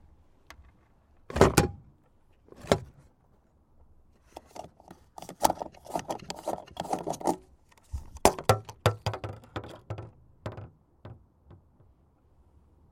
描述：塑料气体容器填充汽车罐汽车cu gurgle +汽车气帽remove.wav
标签： 填充 容器 汽车 汩汩 塑料 删除 气体 汽车
声道立体声